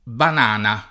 banana [ ban # na ] s. f.